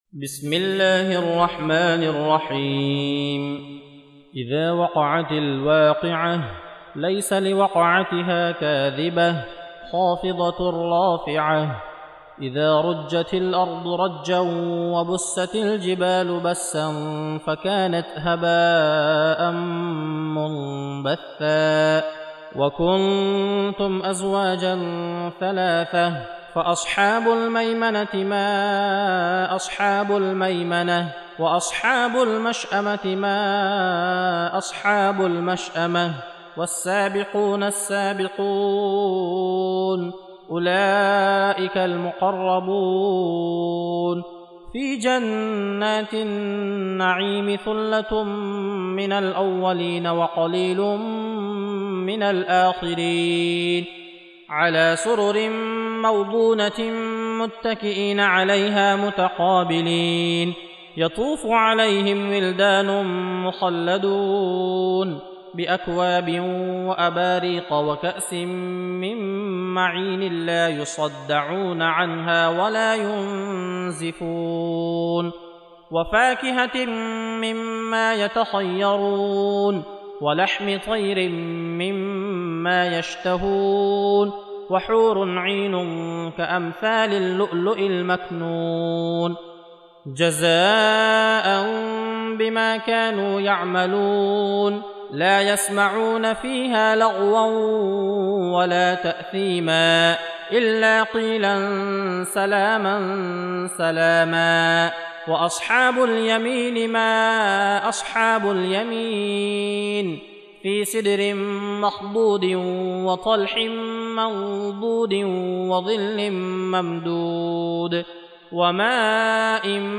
56. Surah Al-W�qi'ah سورة الواقعة Audio Quran Tarteel Recitation
Surah Sequence تتابع السورة Download Surah حمّل السورة Reciting Murattalah Audio for 56.